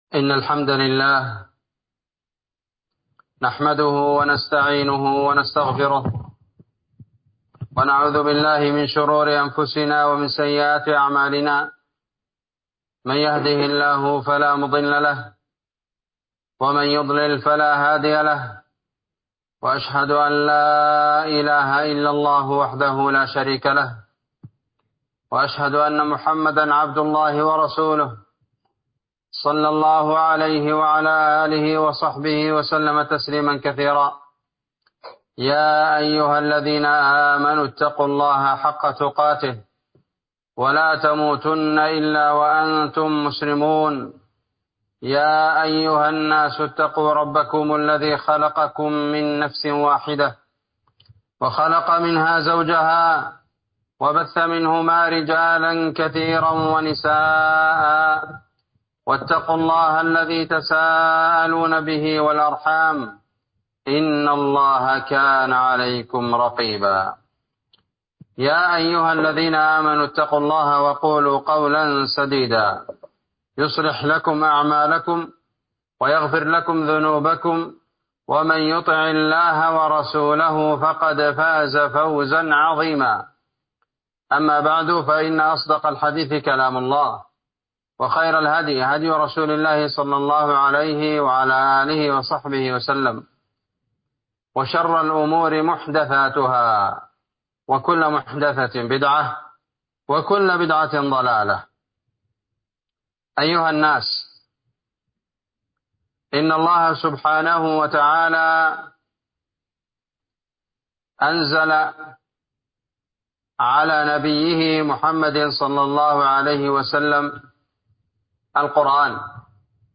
خطبة بعنوان تفسير سورة العصر ٢ صفر ١٤٤٥
إندونيسيا- جزيرة سولاويسي- مدينة بوني- قرية تيرونج- مسجد الإخلاص